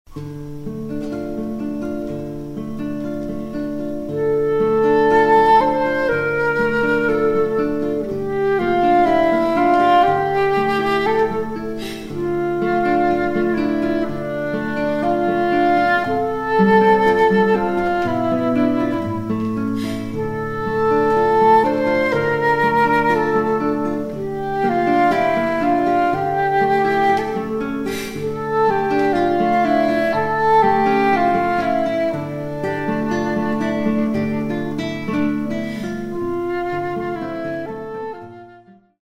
music for ceremonies and celebrations